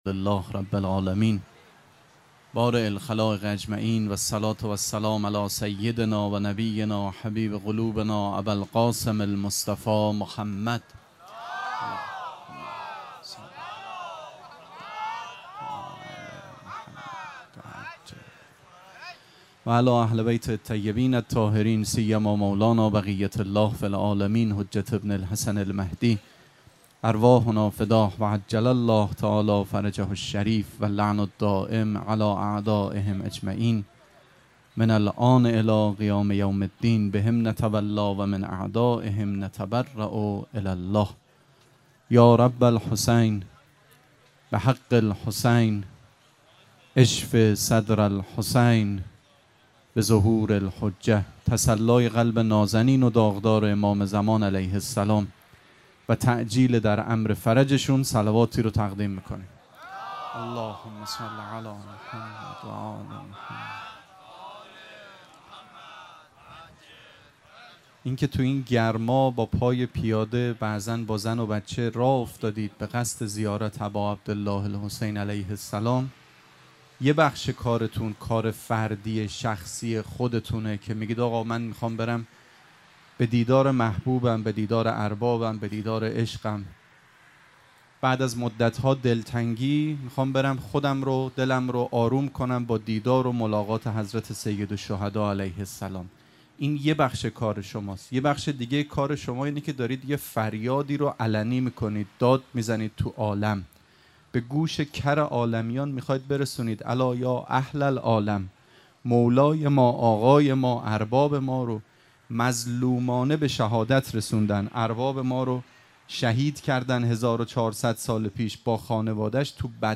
سخنرانی
شب دوم مراسم عزاداری اربعین حسینی ۱۴۴۷ شنبه ۱۸ مرداد ۱۴۰۴ | ۱۵ صفر ۱۴۴۷ موکب ریحانه الحسین سلام الله علیها
sokhanrani2.mp3